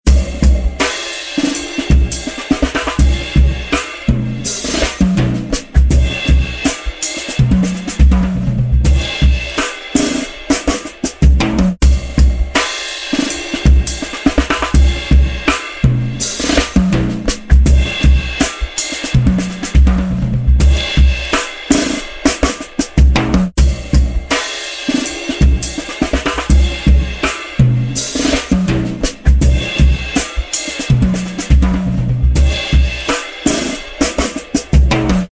モダンなミックスのためのクラシックなフランジング
Instant Flanger Mk II | Drums | Preset: Woozy
Instant-Flanger-Mk-II-Eventide-Drums-Woozy.mp3